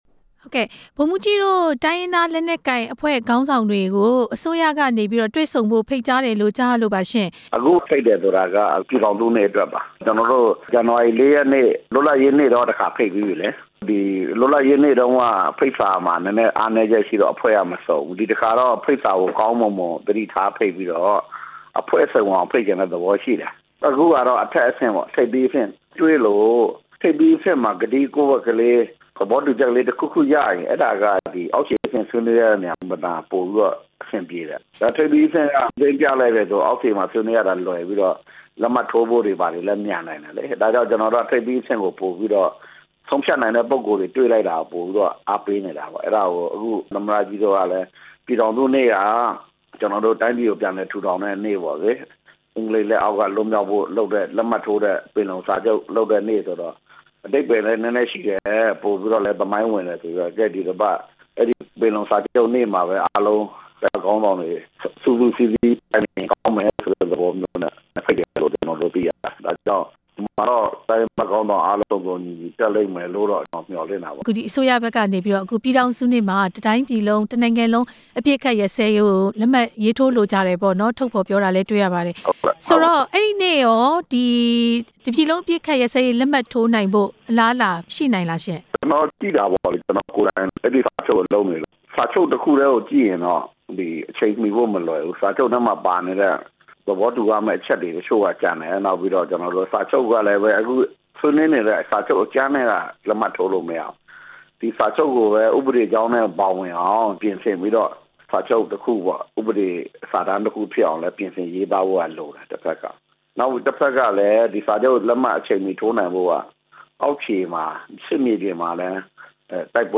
ဆက်သွယ်မေးမြန်းထားပါတယ။်